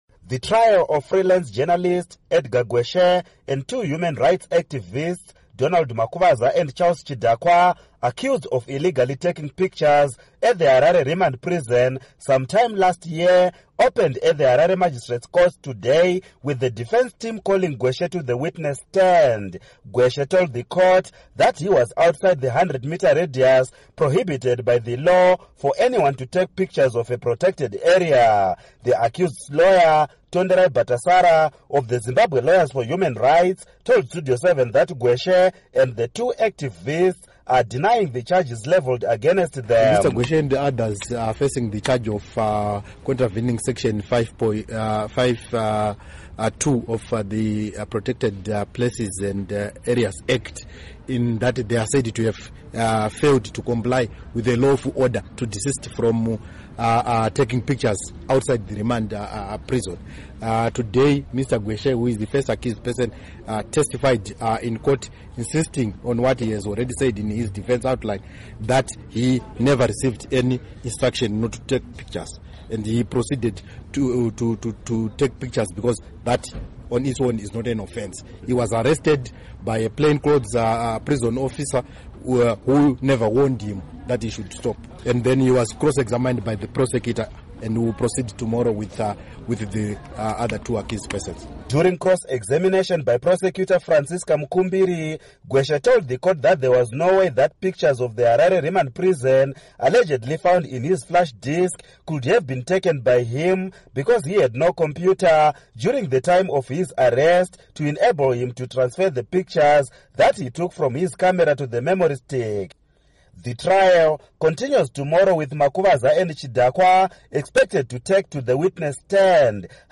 Report on Trial of Journalist, Political Activists